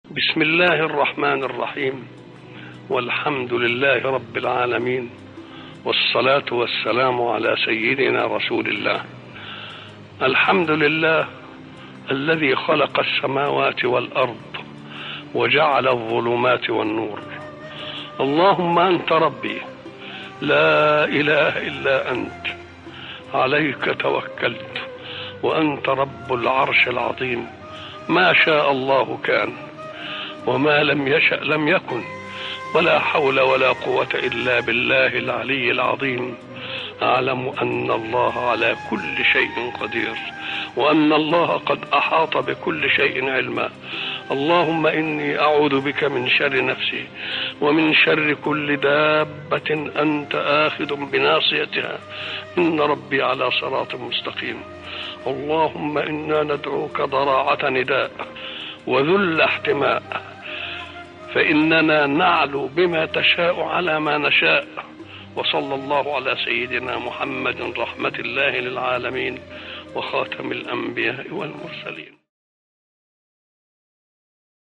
دعاء جامع يبدأ بحمد الله والثناء عليه، ويؤكد على التوحيد والتوكل على رب العرش العظيم. يتضمن الدعاء الاستعاذة من الشرور والطلب بالثبات على الصلاة والهداية، مختتماً بالصلاة على النبي محمد صلى الله عليه وسلم.